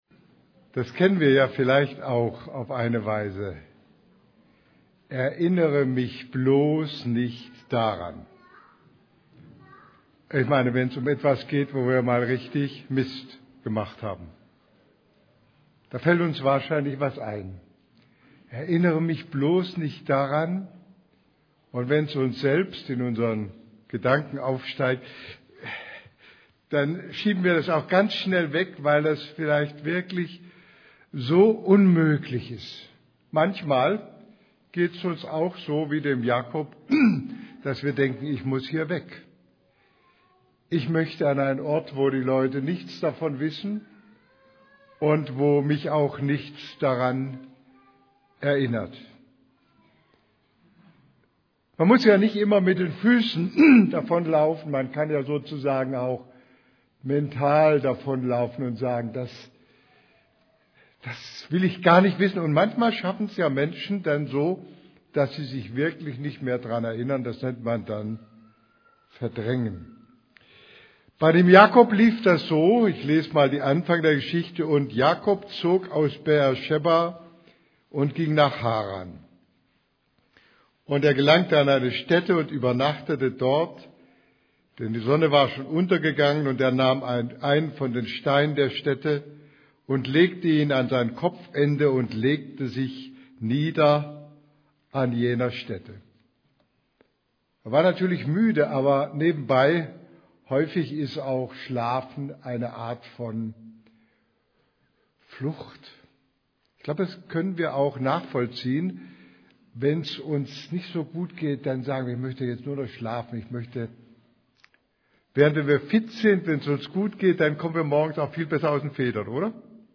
Predigt vom 28.